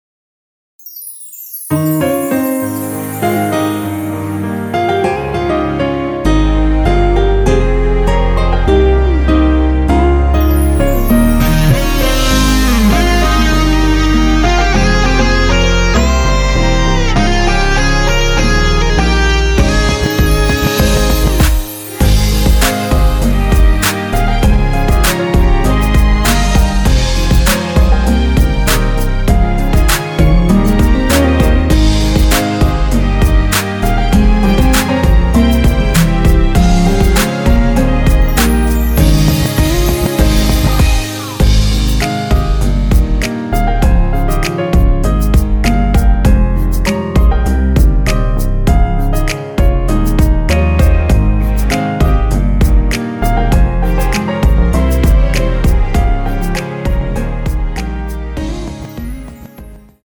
원키가 높으신 여성분들은 이곡으로 하시면 될것 같습니다.(미리듣기로 충분히 연습해보세요)
Ab
◈ 곡명 옆 (-1)은 반음 내림, (+1)은 반음 올림 입니다.
앞부분30초, 뒷부분30초씩 편집해서 올려 드리고 있습니다.